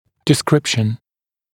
[dɪ’skrɪpʃn][ди’скрипшн]описание, характеристика